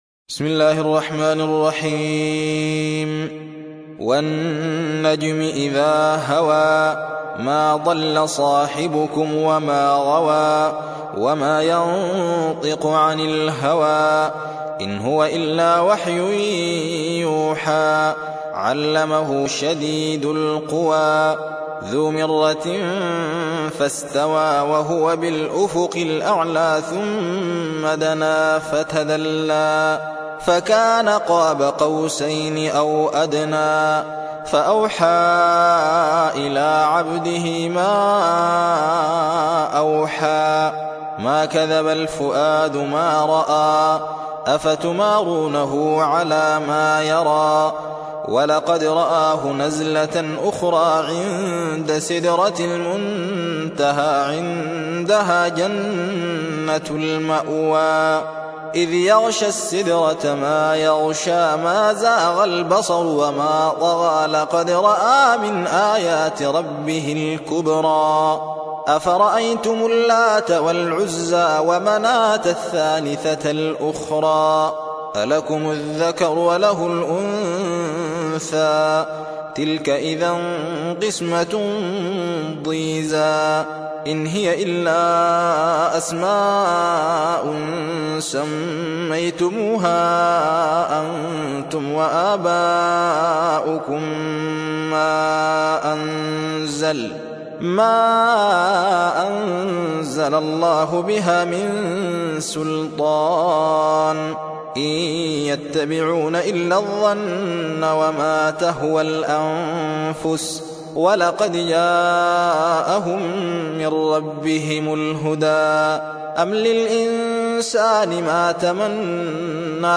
53. سورة النجم / القارئ